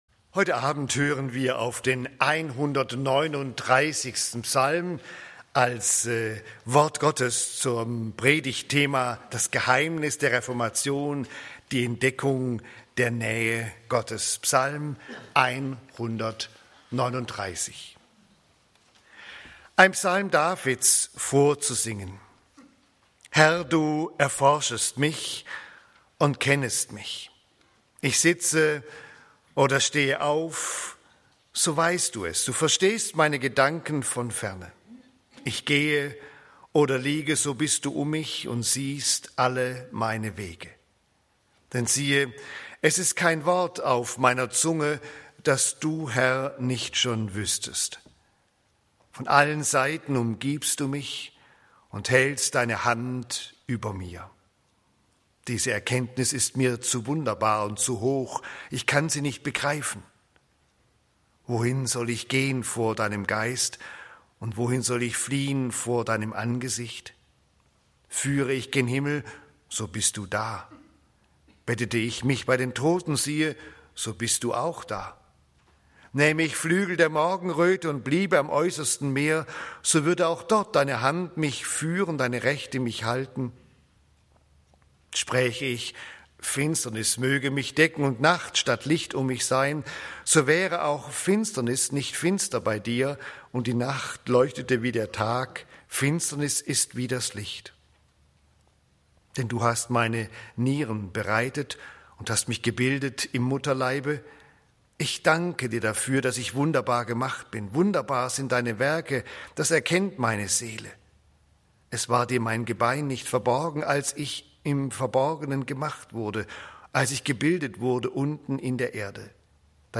Gottesdienste 팟 캐스트